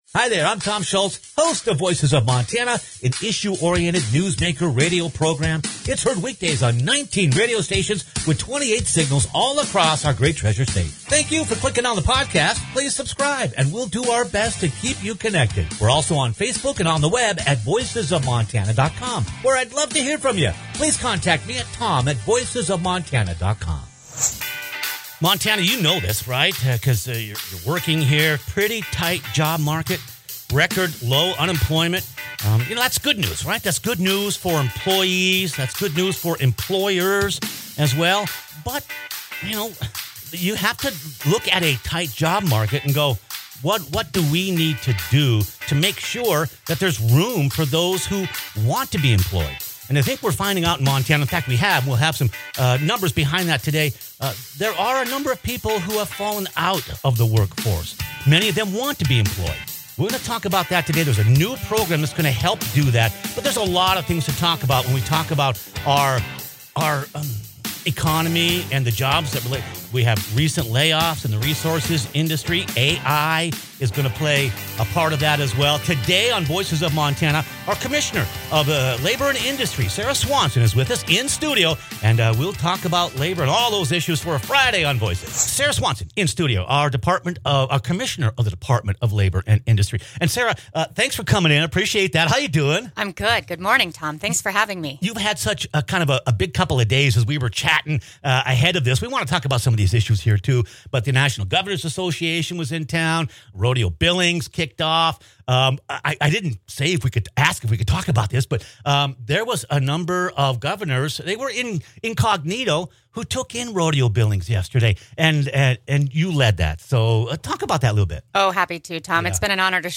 Montana has record low unemployment, but the job market is tight, and employers have roughly 70-thousand job openings in the state. Montana Department of Labor and Industry Commissioner Sarah Swanson joins us in-studio as we go in-depth on a new 406 JOBS initiative that aims to help those who have fallen out of the workforce